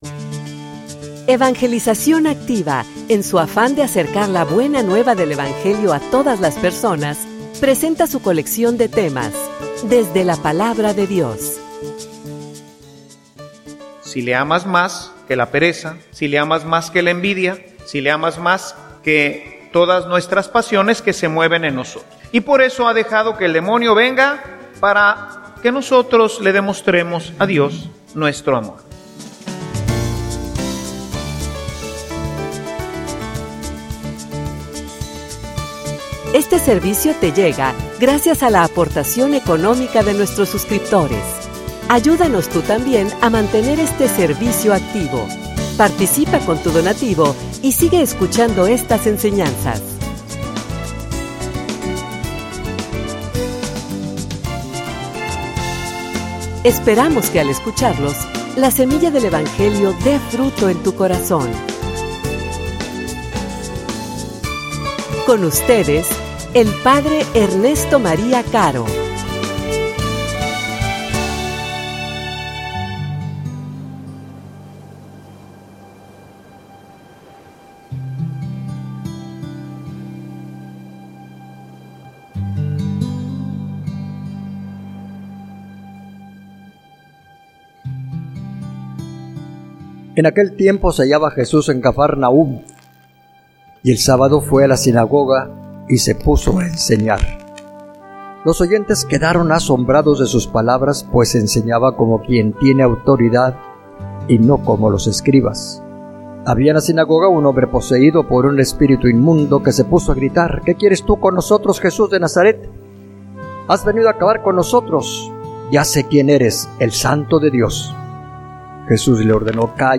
homilia_Como_llega_el_demonio_a_tu_vida.mp3